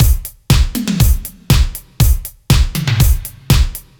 Index of /musicradar/french-house-chillout-samples/120bpm/Beats